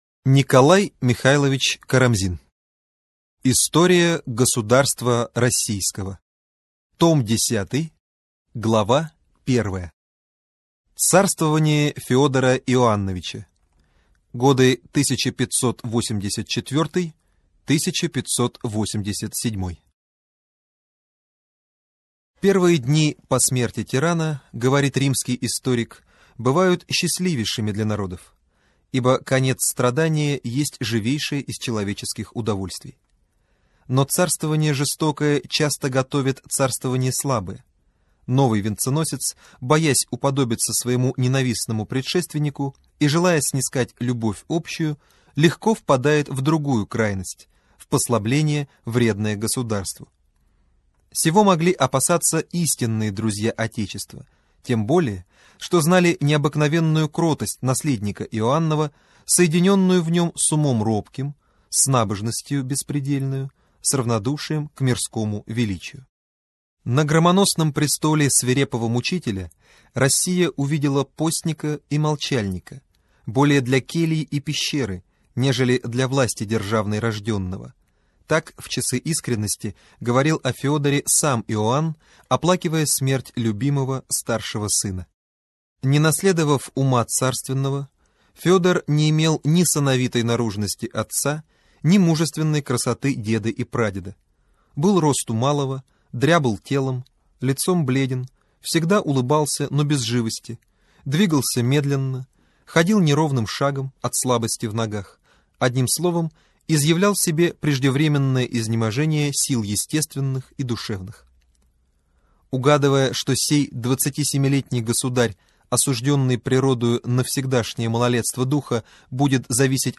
Аудиокнига История государства Российского. Том 10. Царствование Федора Иоанновича. 1584-1598 гг.